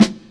001_Lo-Fi Cold Snare_1.L.wav